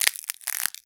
STEPS Glass, Walk 08.wav